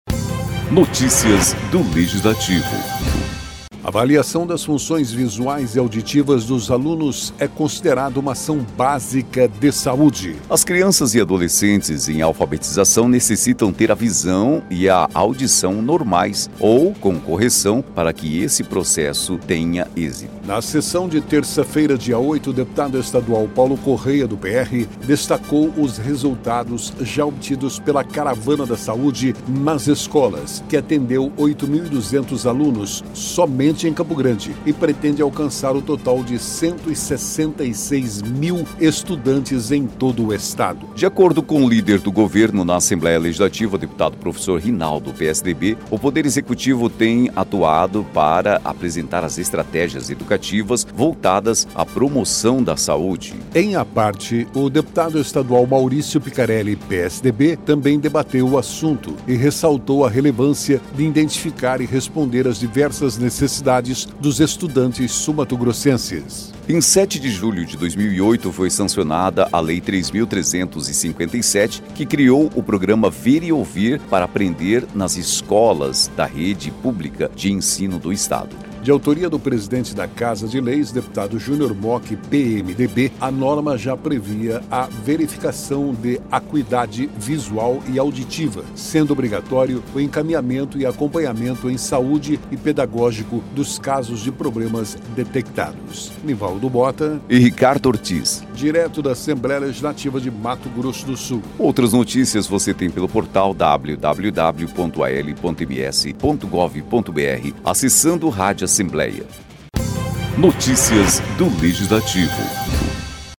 Na sessão desta terça-feira (8), o deputado estadual Paulo Corrêa (PR) destacou os resultados já obtidos pela Caravana da Saúde nas Escolas, que atendeu 8.200 alunos somente em Campo Grande e pretende alcançar o total de 166 mil estudantes em todo o Estado.